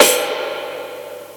Batmans DarkSoul Perc 15.wav